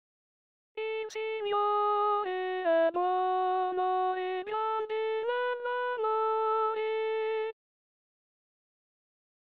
Messa Domenica 23 febbraio 2020
SALMO RESPONSORIALE